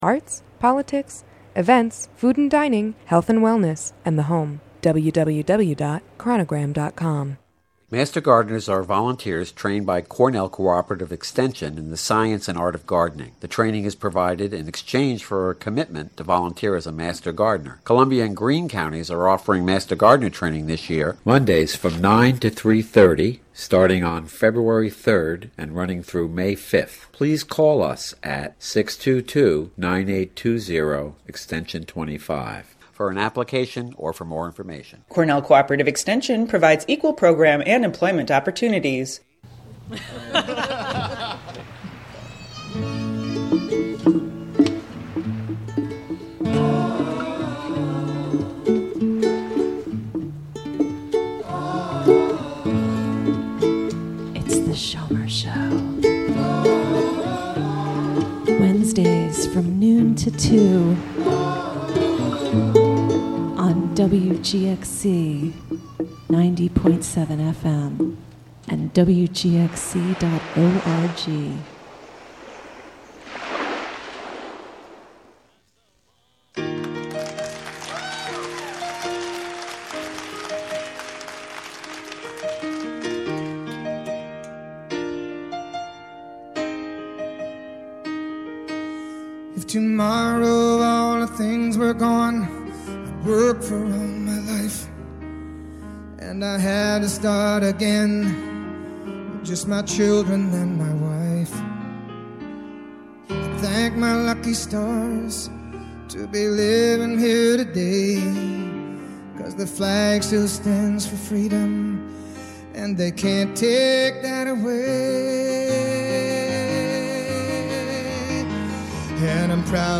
State Senator Kathleen A. Marchione visits the Hudson studio to discuss the issues currently facing the state of New York.